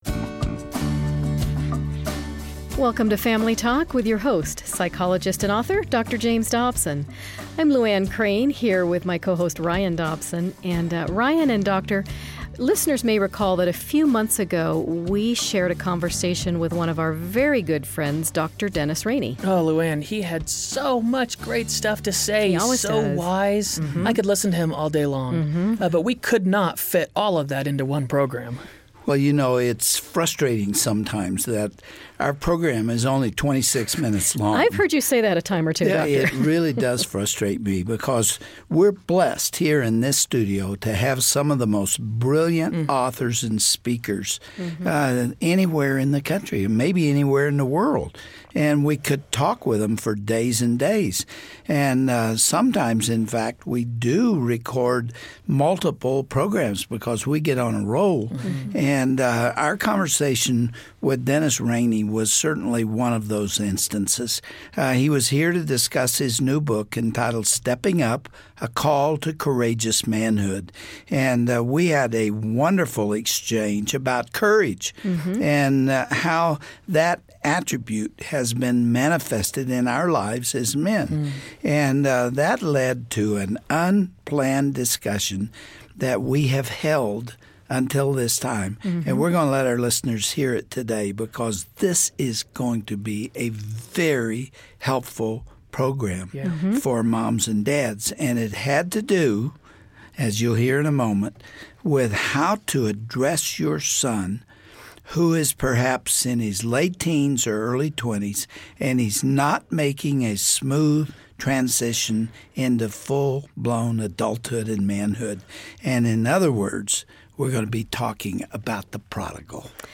Nothing quite breaks a parent's heart like a prodigal child. Tune into this special broadcast as Dr. Dobson and Dennis Rainey address this issue and share their own experiences about how tough love and prayer can bring a wayward child back to the straight and narrow.